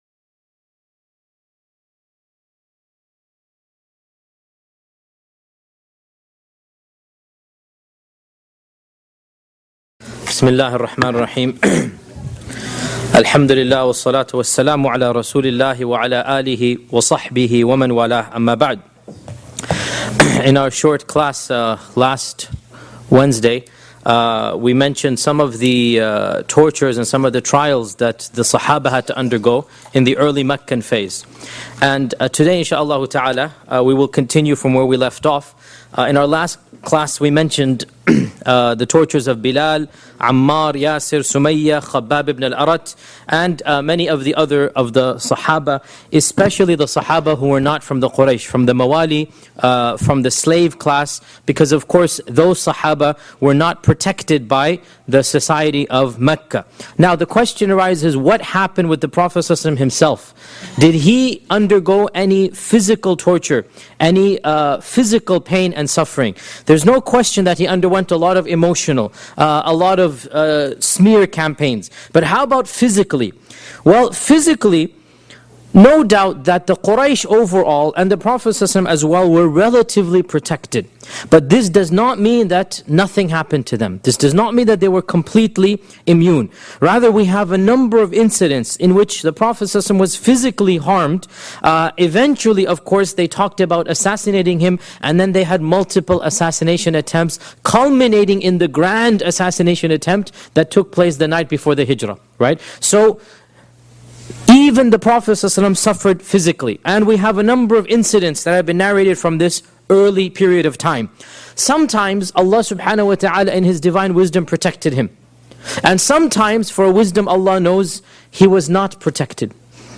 292 views High Quality: Download (285 MB) Medium Quality: Download (51.85 MB) MP3 Audio (01:13:01): Download (50.02 MB) Transcript: Download (0.32 MB) Seerah of Prophet Muhammad 14 Shaykh Yasir Qadhi gives a detailed analysis of the life of Prophet Muhammed (peace be upon him) from the original sources. Title: Torture towards the Prophet (pbuh) & Migration to Abyssinia Study the biography of the single greatest human being that ever walked the surface of this earth, whom Allah sent as a Mercy to Mankind. This lecture was recorded on 16th November, 2011 Shaykh Yasir Qadhi gives a detailed analysis of the life of Prophet Muhammed (peace be upon him) from the original sources.